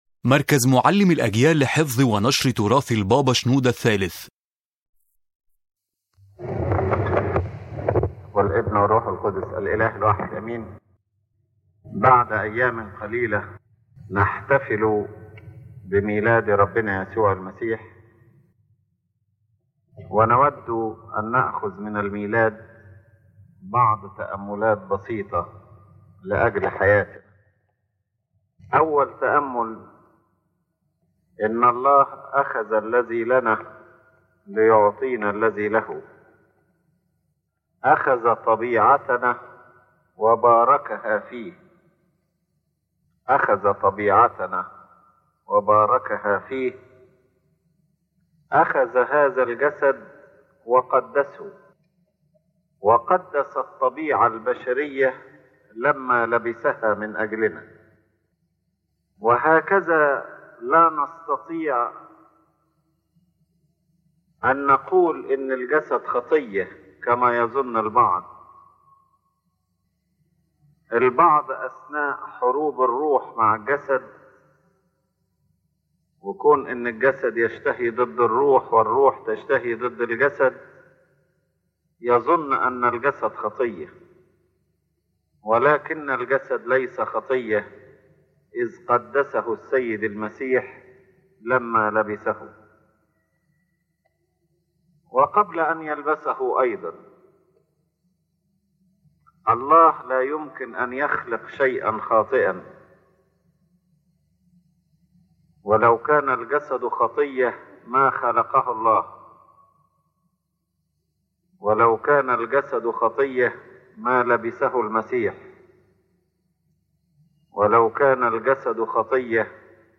His Holiness Pope Shenouda III presents spiritual meditations on the meaning of the birth of our Lord Jesus Christ, explaining that God took our nature and blessed it when He became incarnate.